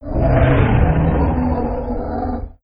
c_trex00_dead.wav